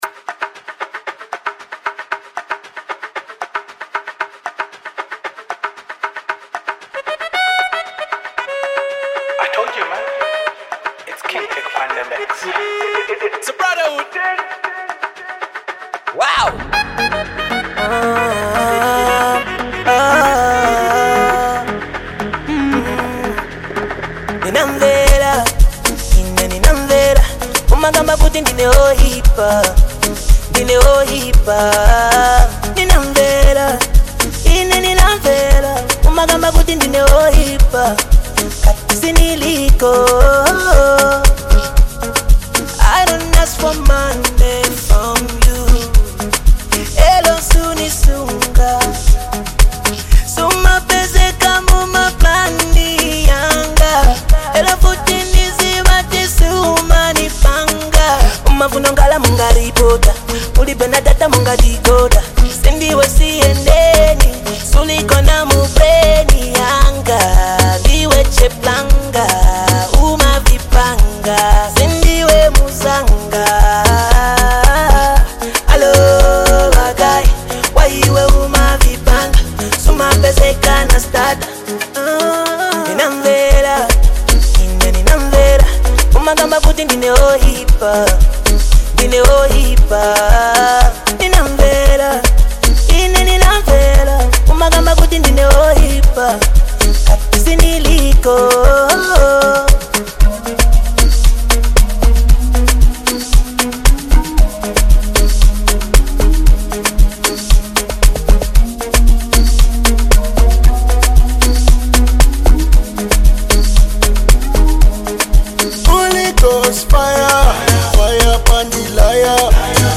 Its soulful melodies and heartfelt lyrics